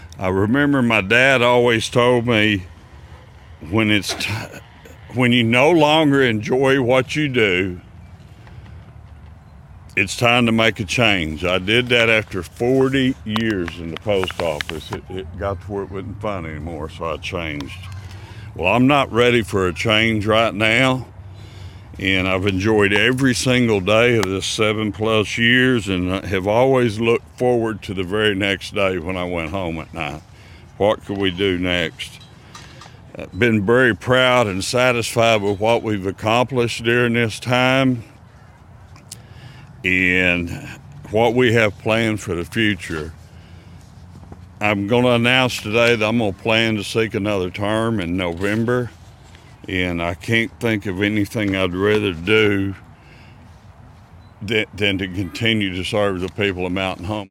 Mayor Hillrey Adams, surrounded by supporters Friday morning, announced his bid for a 3rd term as Mayor of Mountain Home. The announcement was made at the All Inclusive Playground at Hickory Park an accomplishment he touted as a highlight of his second term.
Mayor Adams discussed with those gathered what motivated him to ask the voters for another opportunity to serve the city.